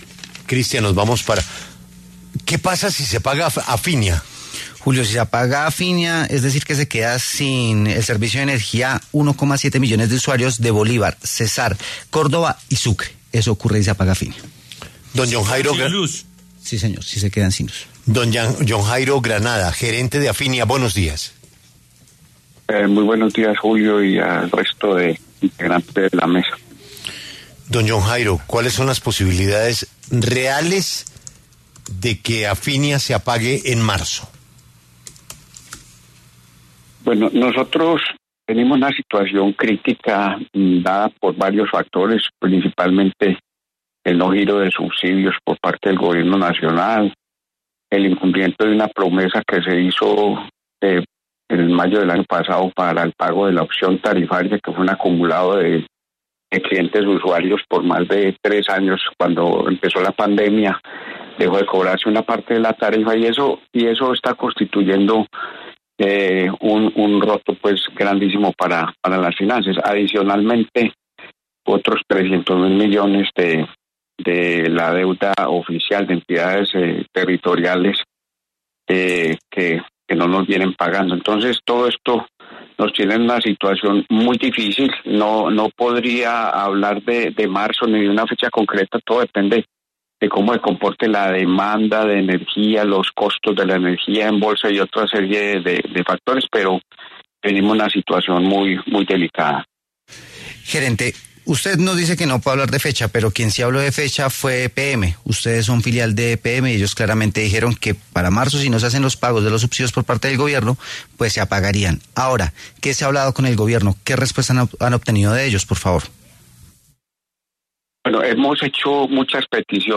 En entrevista con W Radio